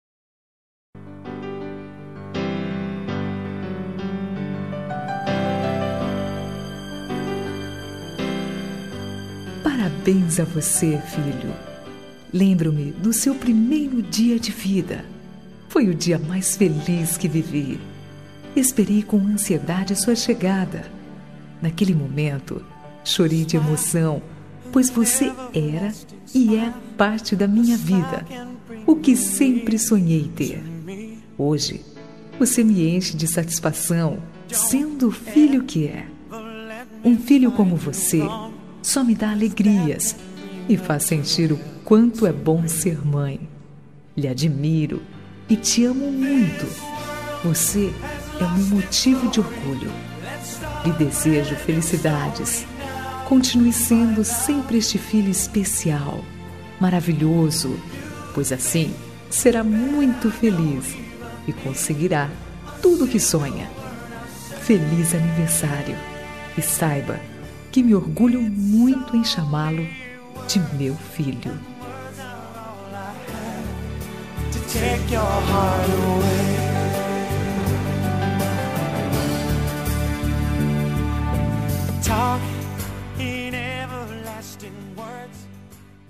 Aniversário de Filho – Voz Feminino – Cód: 5210 Linda